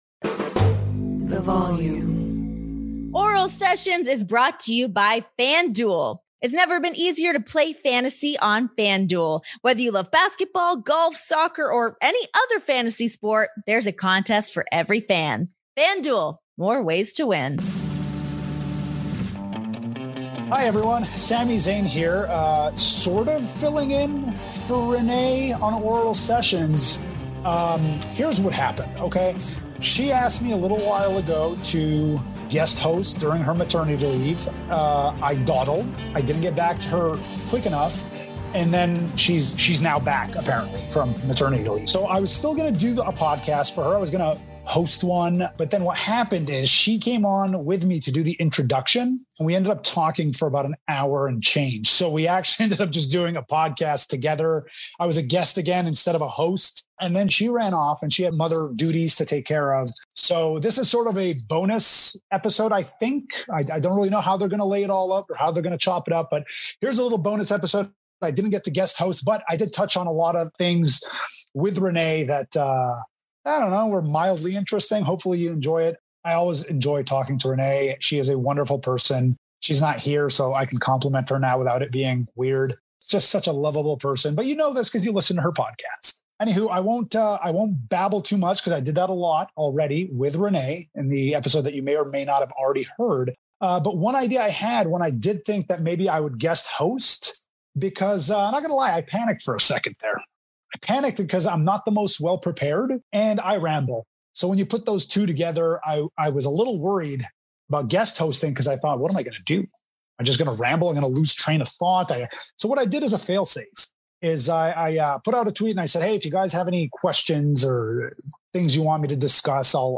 After a mind-bending conversation with Renee, Sami Zayn finally takes over hosting duties by fielding some fan Q&A. The former WWE Intercontinental Champion talks about everything from creating his new character to personal growth, how dating sites connect to nature vs. nurture and whether he was inspired by indie legend El Generico … or vice versa.